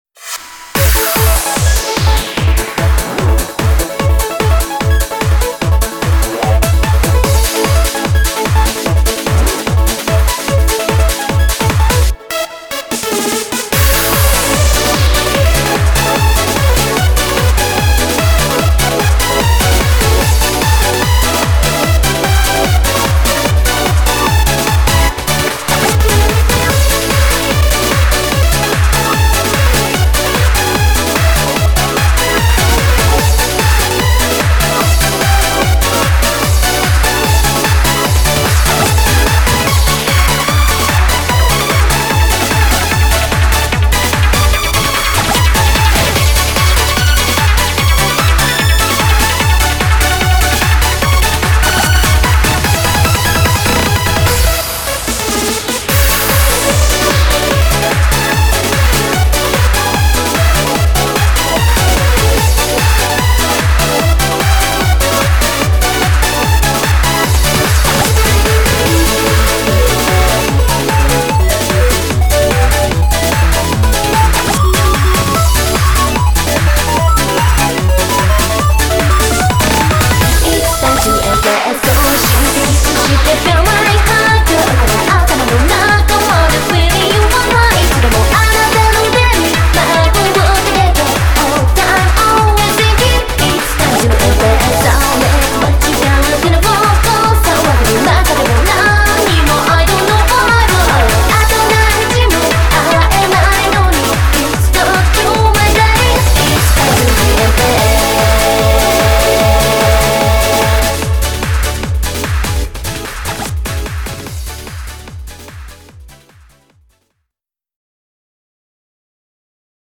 BPM148
Audio QualityPerfect (High Quality)
Genre; TRANCE.